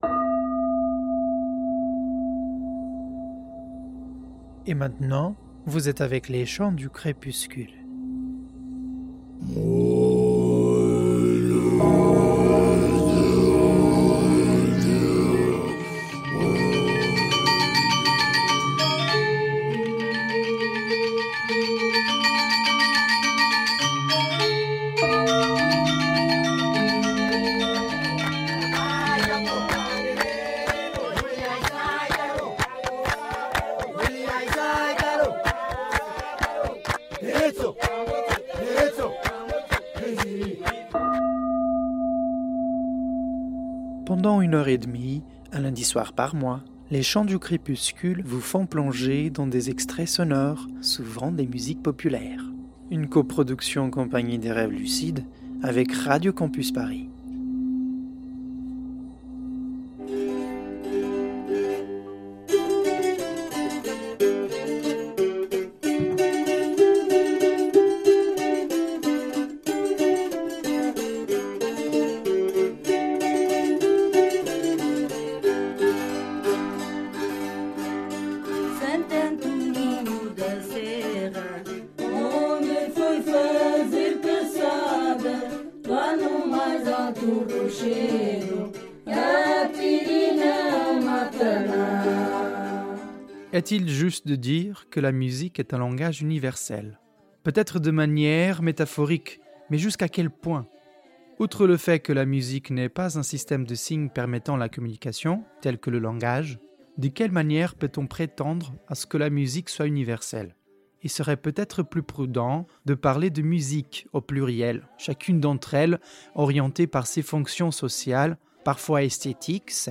Mais pour comprendre sa relation et son investissement sur ce genre musical, il faut déjà comprendre ce que c’est que le tango. À la fin de l'émission, il nous emmène aux alvéoles du quai St. Bernard, pour assister à une milonga de plein air.